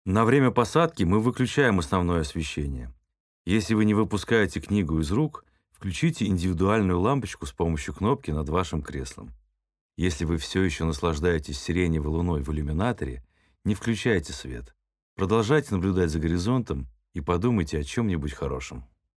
В самолётах «Победы» появилась новая озвучка: спортивного комментатора Дмитрия Губерниева заменил певец Леонид Агутин.
Замену скучного бубнежа в трубку интеркома, который никто не слушает, на PRAM-записи (pre-recorded announcements) голосами звёзд придумали для привлечения внимания клиентов к озвучиваемым на борту правилам компании и демонстрации аварийно-спасательного оборудования.
PRAM-12-Dimming-of-cabin-lights-landing.mp3